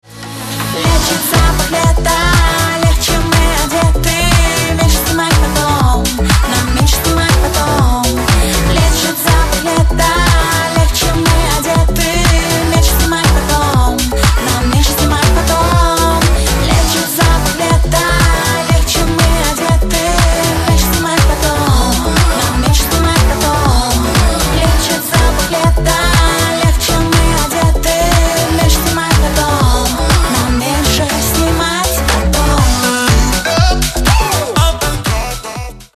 • Качество: 128, Stereo
поп
женский вокал
dance